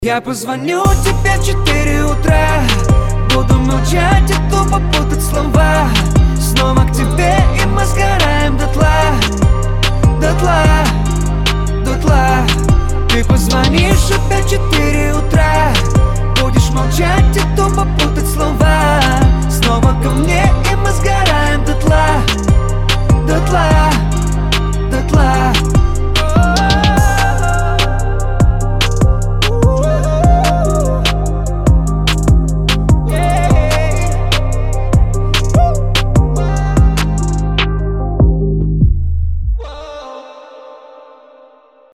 лирика
Хип-хоп
чувственные
Bass